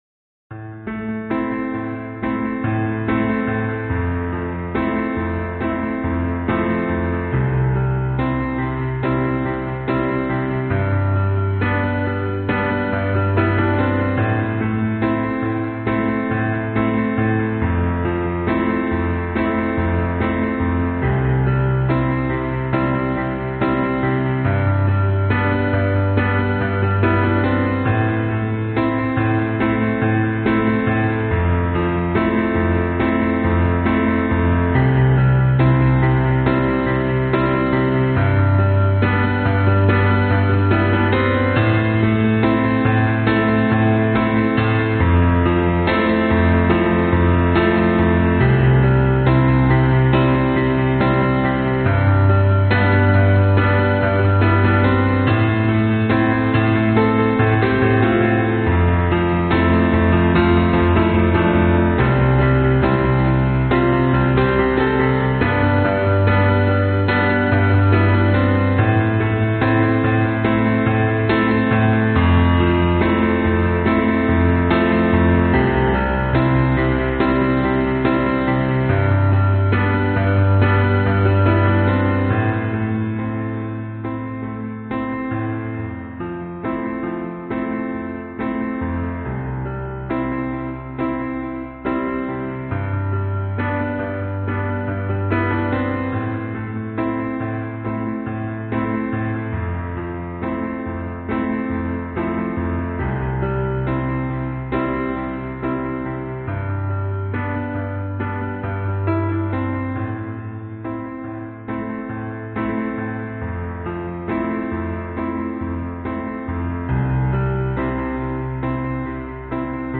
最后，我把整首曲子都围绕着他的架子鼓来做。
标签： 摇滚 艺术 流行 电子 低音 合成器 实验 时髦
声道立体声